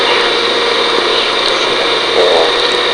EVP's
We were investigating a local home but we were outside at a neighbors house when we got these.